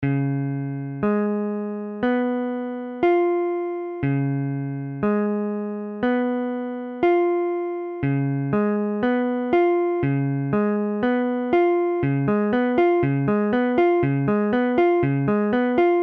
Illustration sonore : IV_Db7.mp3
Db7 : accord de R� b�mol septi�me Mesure : 4/4
Tempo : 1/4=60